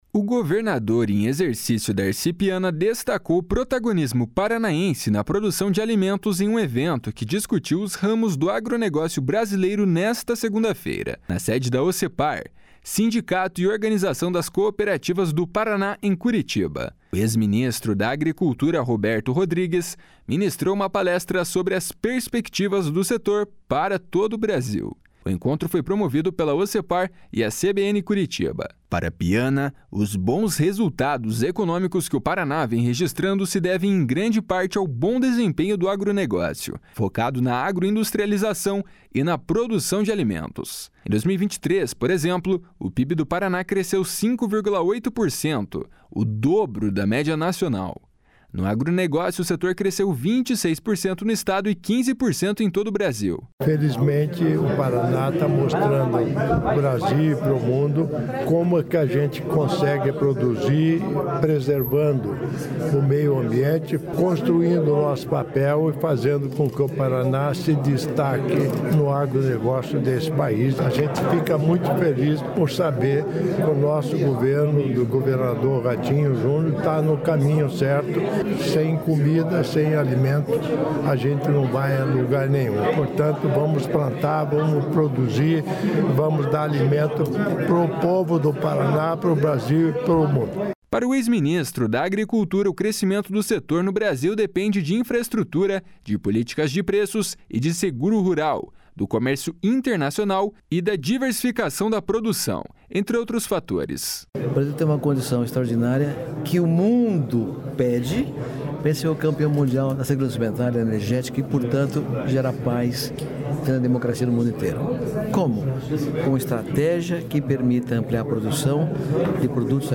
O governador em exercício Darci Piana destacou o protagonismo paranaense na produção de alimentos em um evento que discutiu os rumos do agronegócio brasileiro nesta segunda-feira, na sede da Ocepar, Sindicato e Organização das Cooperativas do Paraná, em Curitiba.
// SONORA DARCI PIANA //
Para o ex-ministro da Agricultura, o crescimento do setor no Brasil depende de infraestrutura, de políticas de preços e de seguro rural, do comércio internacional e da diversificação da produção, entre outros fatores. // SONORA ROBERTO RODRIGUES //